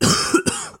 Cough heavy.wav